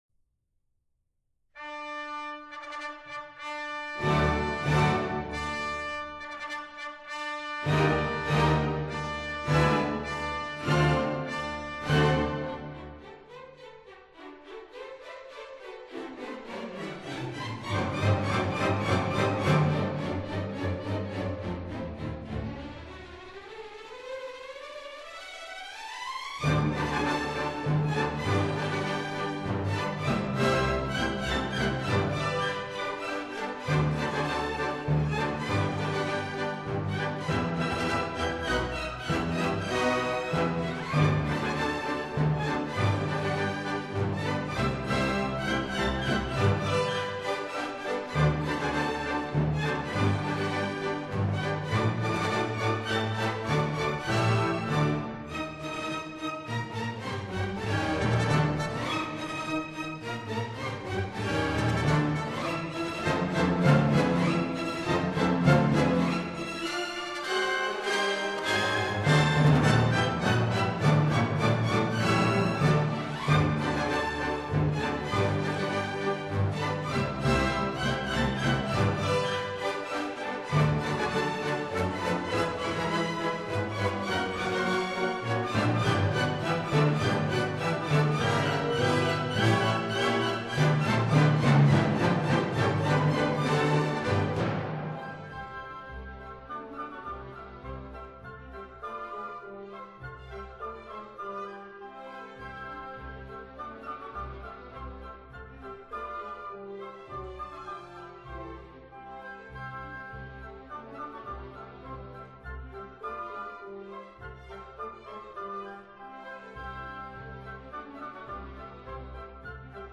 並標明了採用數碼直接位元流方式錄製（簡稱ＤＳＤ技術）。